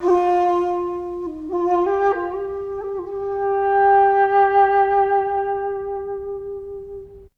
ZG FLUTE 6.wav